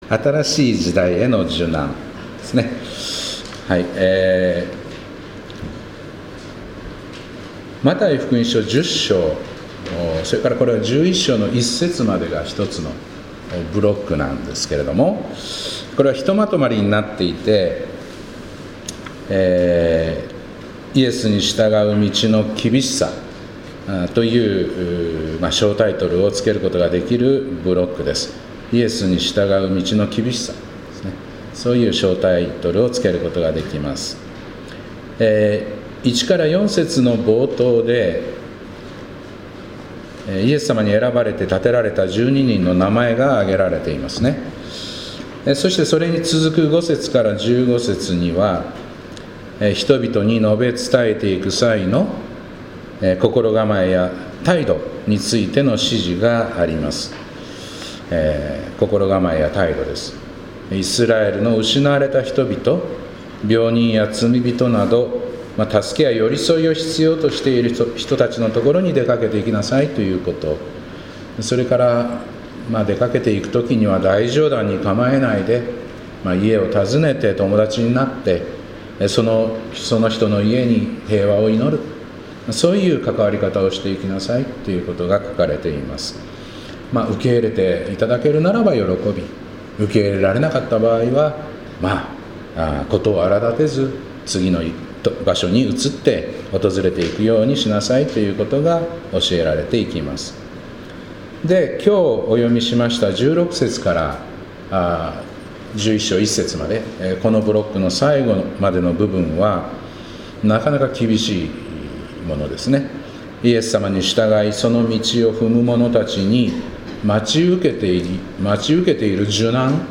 2025年2月9日礼拝「新しい時代への受難」